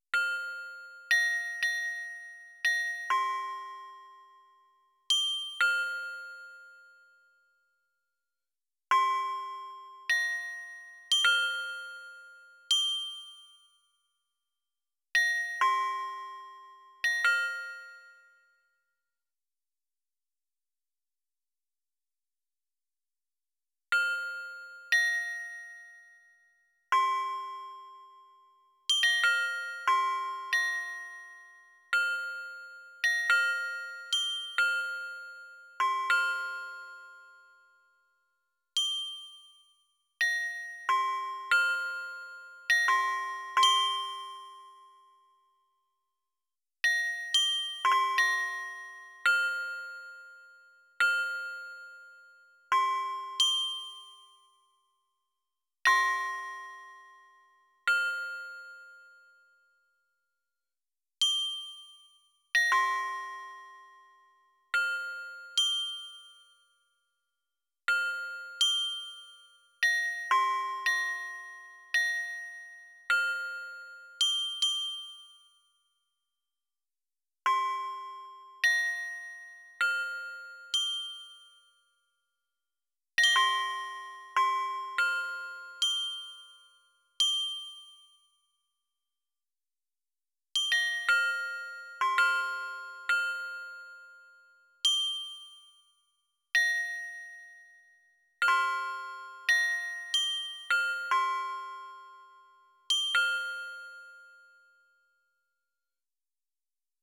Harnessing (or sieving) randomness – wind chimes
windchimes.wav